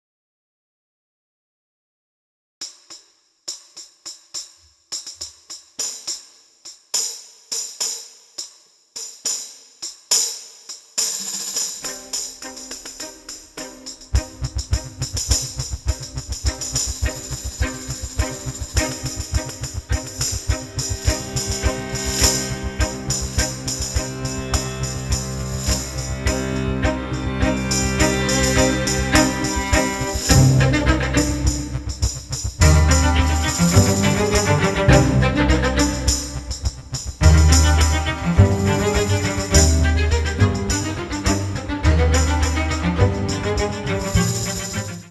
intense, emotional and moving large-orchestral scores
The music was recorded at Air Studios, London